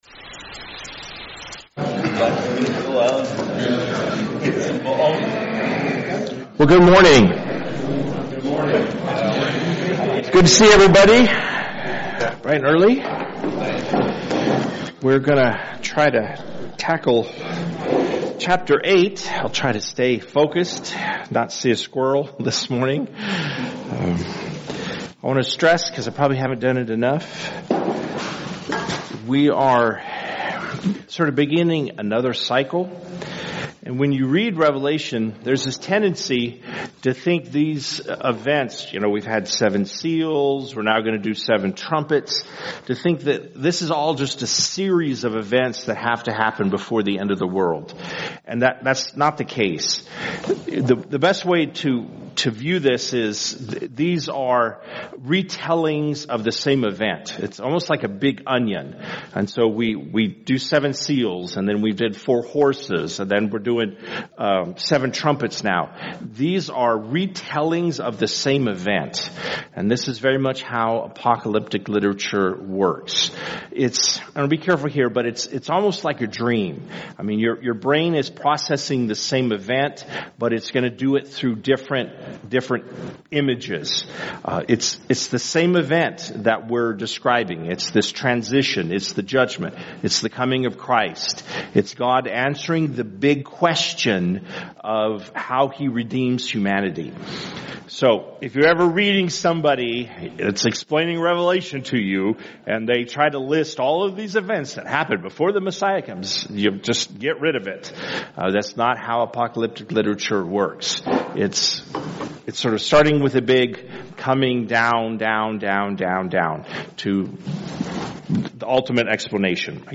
Men’s Breakfast Bible Study 8/27/24